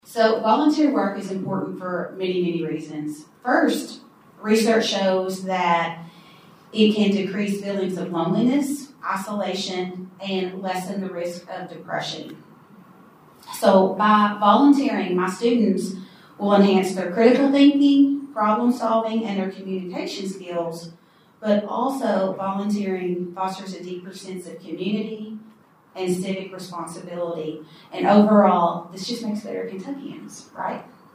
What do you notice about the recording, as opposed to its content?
The United Way of the Coalfield Award Celebration took place at the Steve Beshear Center for Post-Secondary Education on the Madisonville Community College campus.